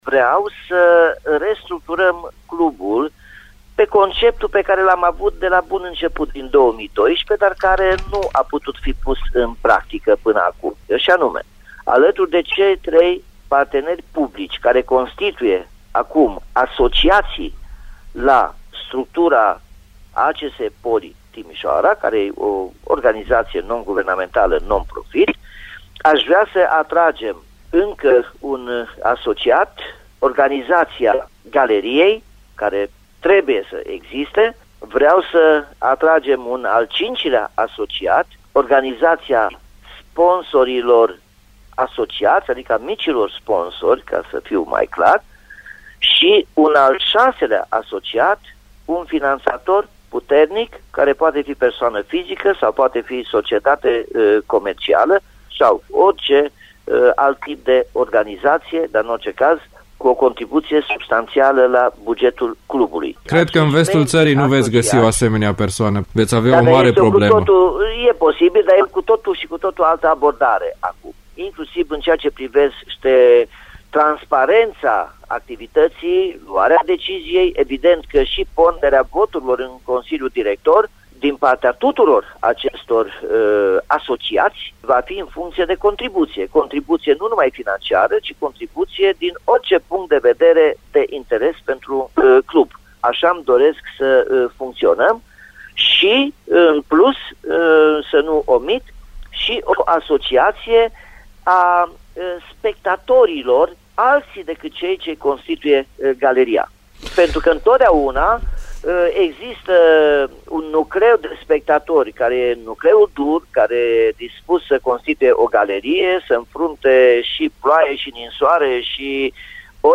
Invitat în ediția de astăzi a emisiunii Arena Radio, primarul Nicolae Robu a sintetizat discuția purtată la mijlocul săptămânii cu liderii Druckeria despre o eventuală revenire a fanilor din Peluza Sud pe stadionul „Dan Păltinișanu”.